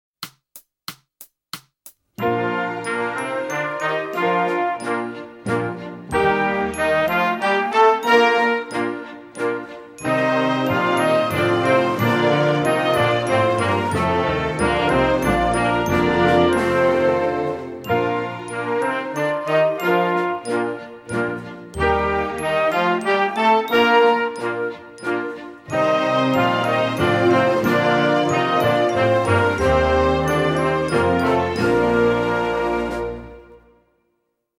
TROMBA SOLO • ACCOMPAGNAMENTO BASE MP3